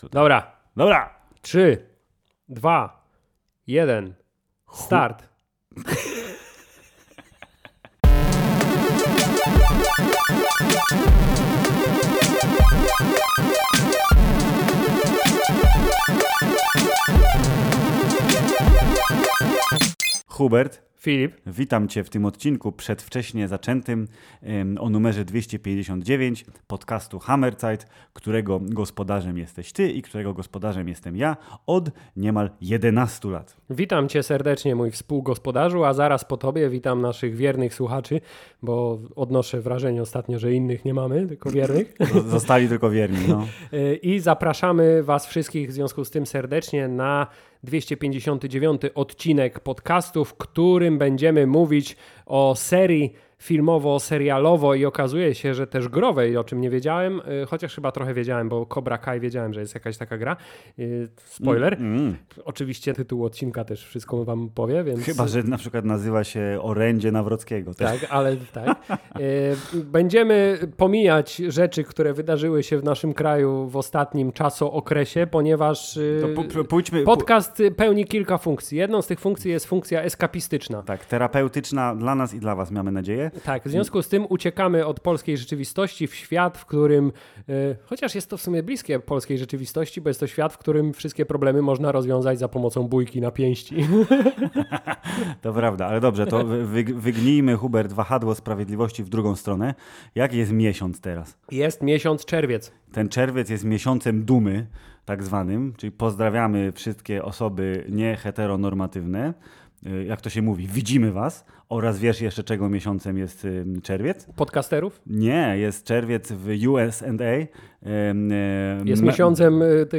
… continue reading 429 에피소드 # Popkultura # Społeczeństwo # Polski # Film # Filmy # Seriale # Recenzje # Rozmowy # Kino # Serial # Rozrywka # Hammerzeit # Polsku